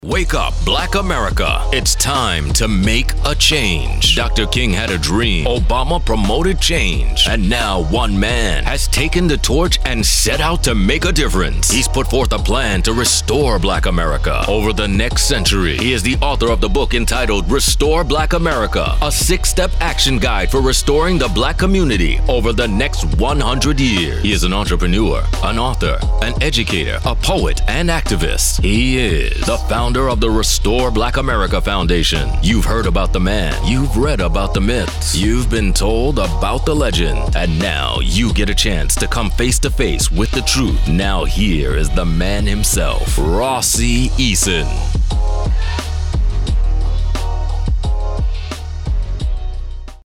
RBA Podcast Intro